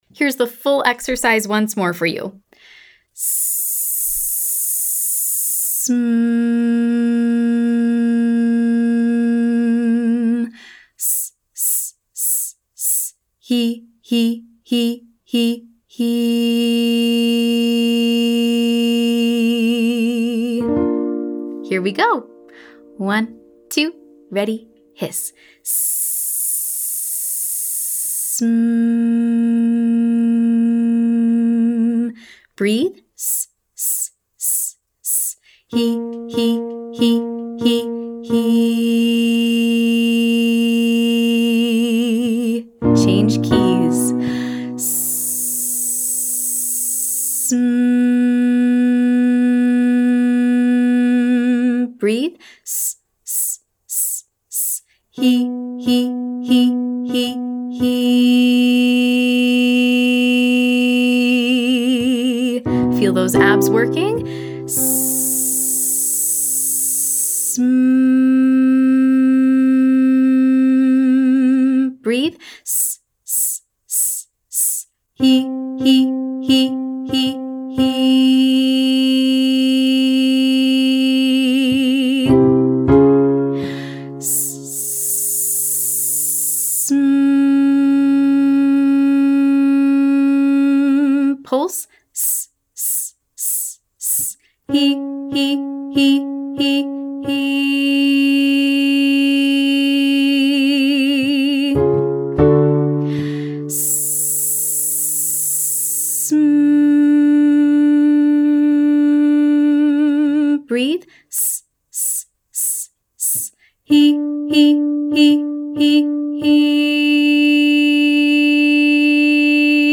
Warmup
Exercise 1: Breath (hiss to hum, hiss & pulse)
Engage your abdominals on a hiss closing to a hum, followed by a hiss & “hee” pulse.  hiss x 4, hee x 4, sustain for 7 on the 5th.